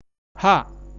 {ha.htô:} sound of medial former {ha.} ह